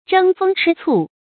争风吃醋 zhēng fēng chī cù 成语解释 因忌妒而明争暗斗（风：风韵；吃醋：常指在男女关系上产生嫉妒情绪）。